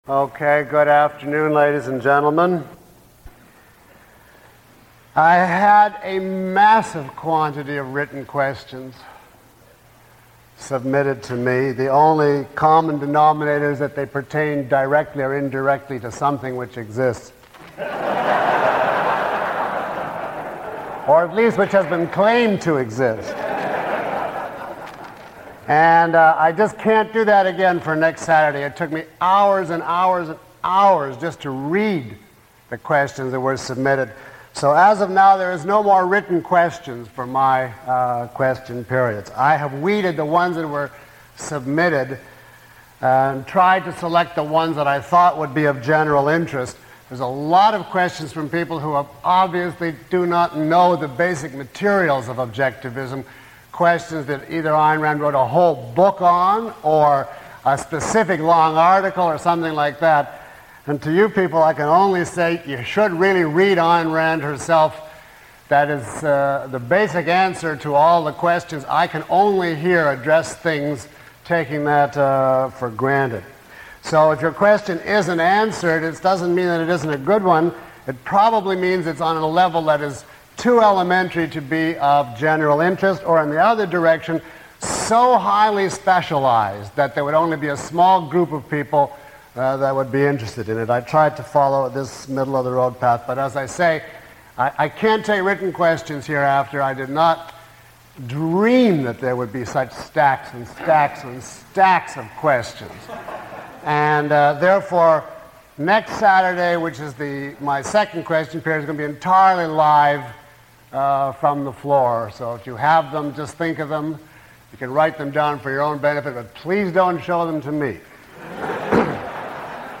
A question and answer session for course attendees.
Read more » In this lecture: A dedicated question and answer session with Dr. Peikoff.
Below is a list of questions from the audience taken from this lecture, along with (approximate) time stamps.